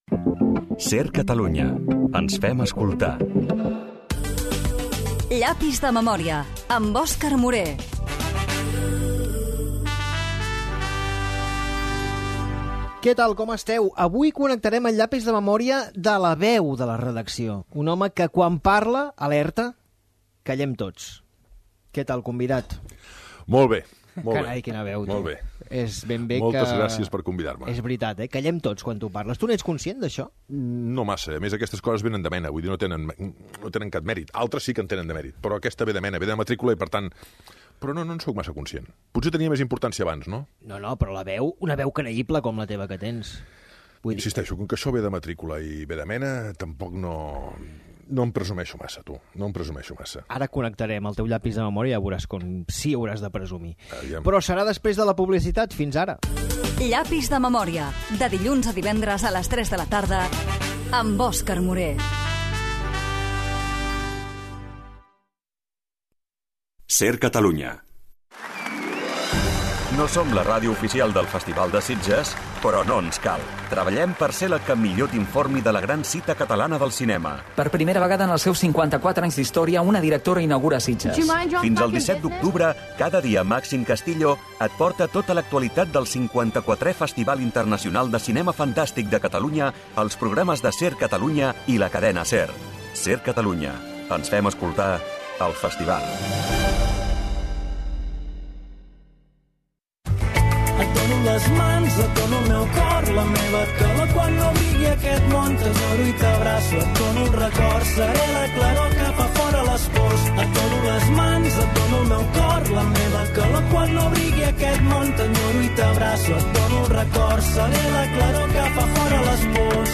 Gènere radiofònic Entreteniment
Banda FM